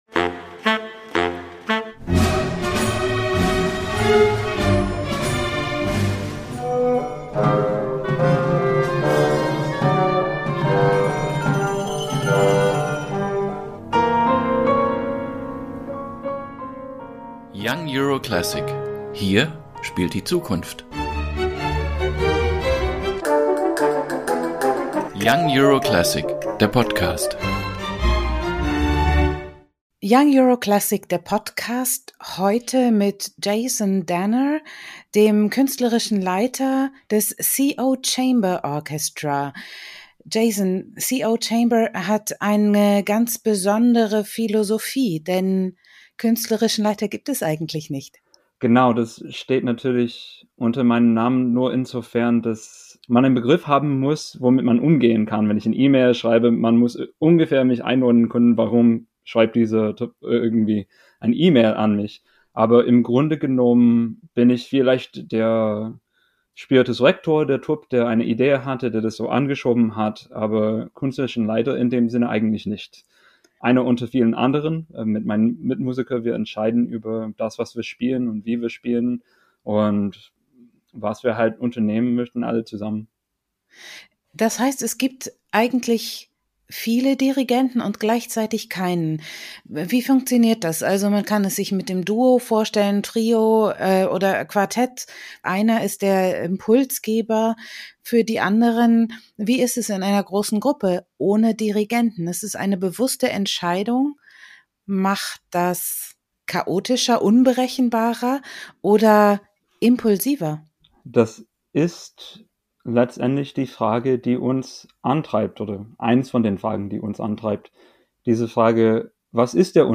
Konzerteinführung 03.08.21 | c/o chamber orc ~ Young Euro Classic. Der Podcast